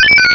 sovereignx/sound/direct_sound_samples/cries/wooper.aif at master